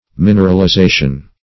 mineralization - definition of mineralization - synonyms, pronunciation, spelling from Free Dictionary
Mineralization \Min`er*al*i*za"tion\, n. [Cf. F.